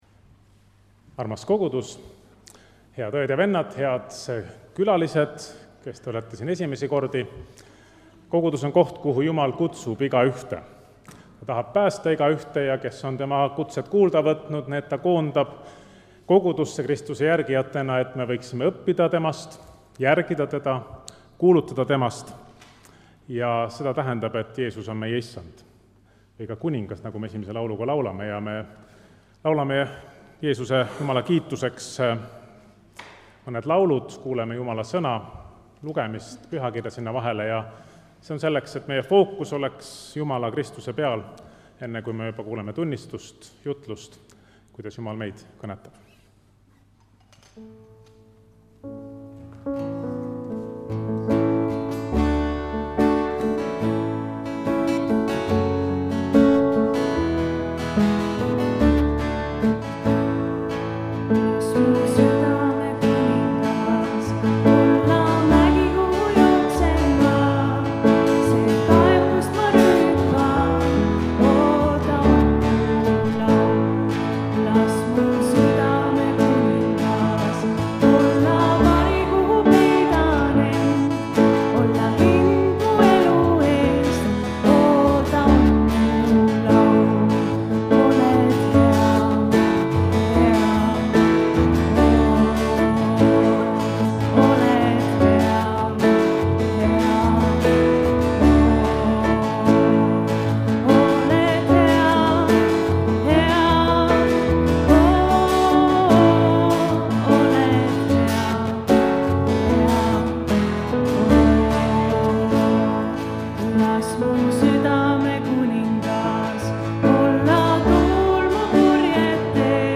Pühakirja lugemine: Js 43:16-21
Muusika: Kolgata noortebänd